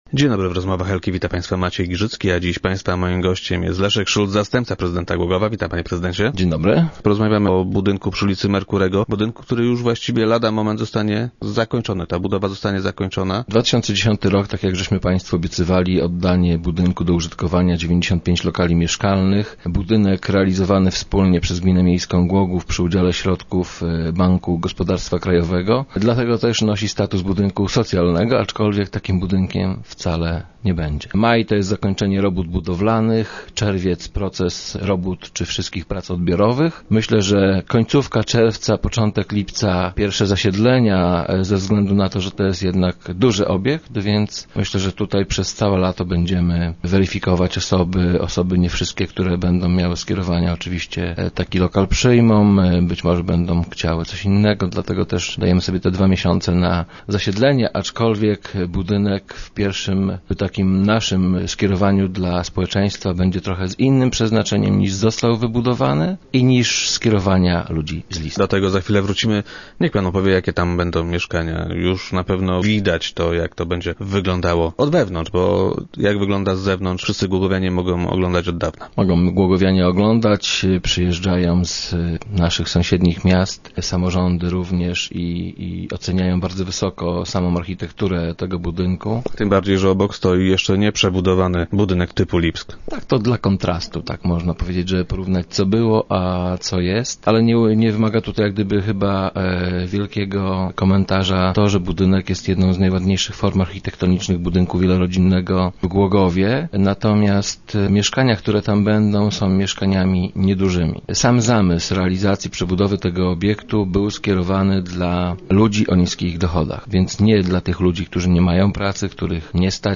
- Tak jak obiecywaliśmy, powstanie w nim 95 mieszkań - powiedział nam Leszek Szulc, zastępca prezydenta Głogowa, który był dziś gościem Rozmów Elki.